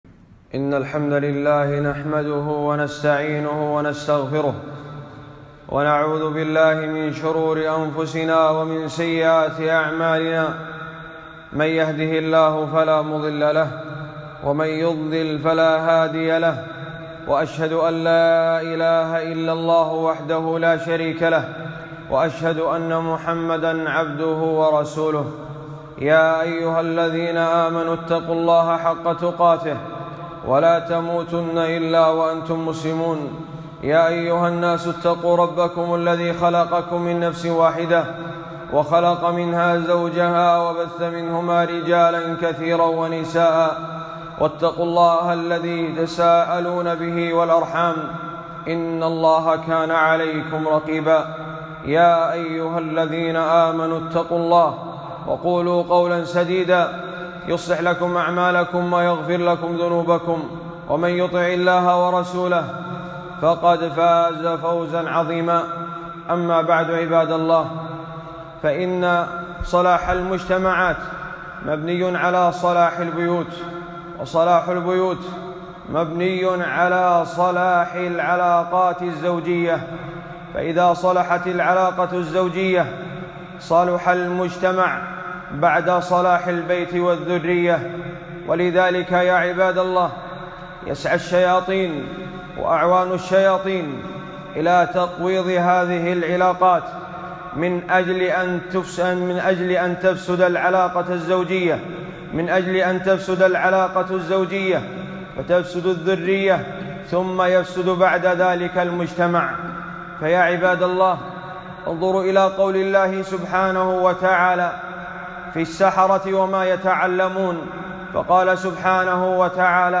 خطبة - المُخبِّبُون أعوان الشياطين